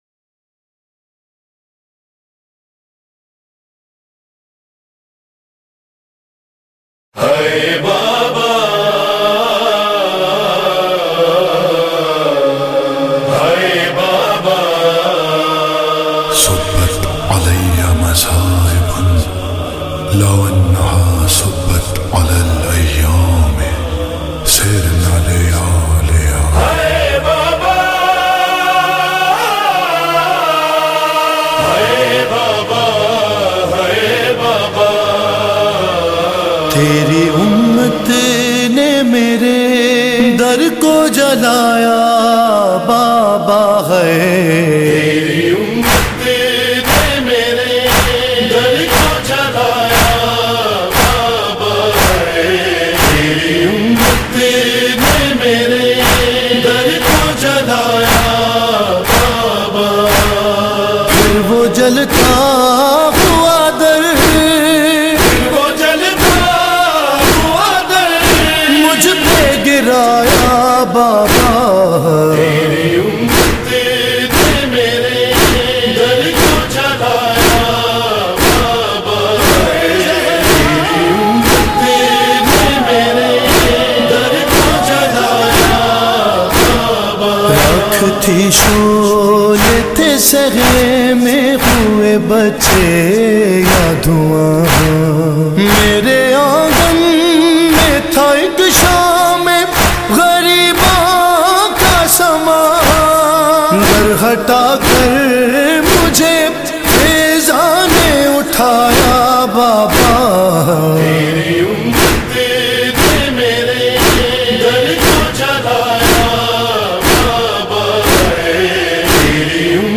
nohay